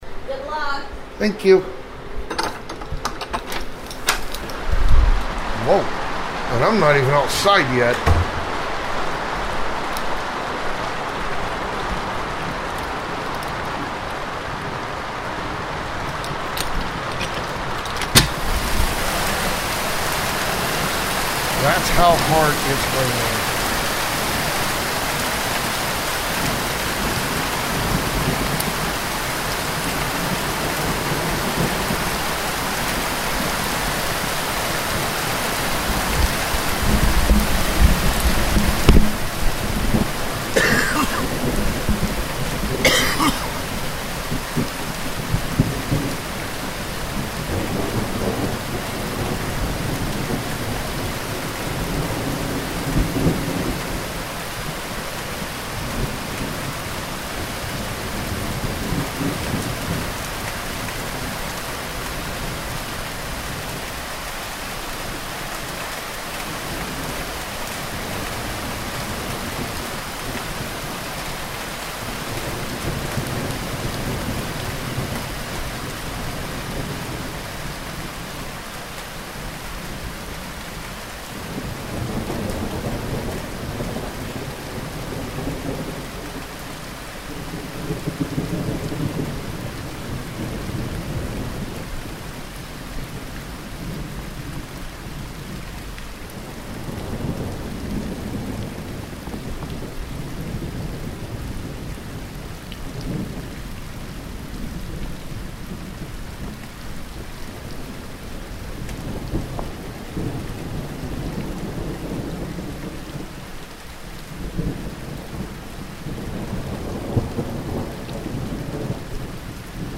Just one huge boom and nothing else?
This happened at 12:54 local time, as I was preparing my lunch. Sound captured from microphones in my back garden.